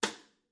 Tag: 边敲击 撞击声 小鼓 VSCO-2 单注 多重采样